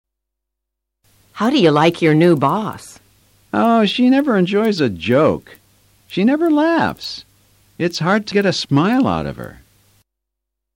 A continuación escucharás a cuatro parejas alabando o criticando a otras personas.